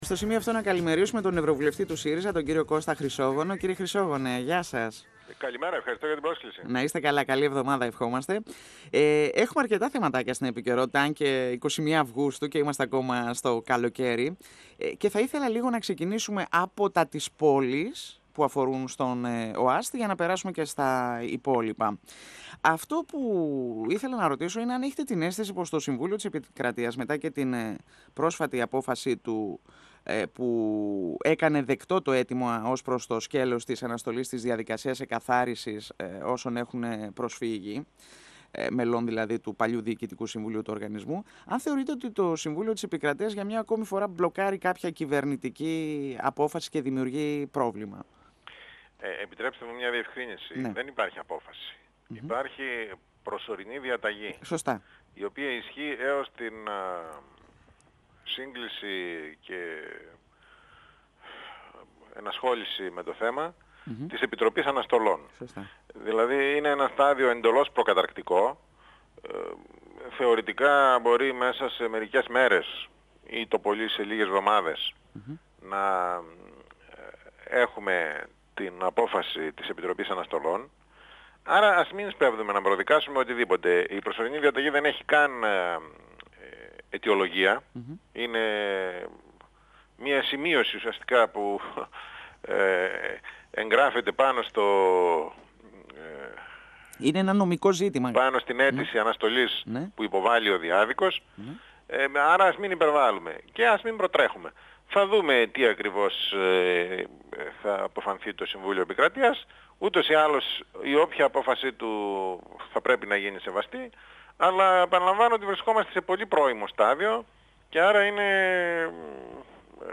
21Αυγ2017 – Ο ευρωβουλευτής του ΣΥΡΙΖΑ Κώστας Χρυσόγονος στον 102 fm της ΕΡΤ3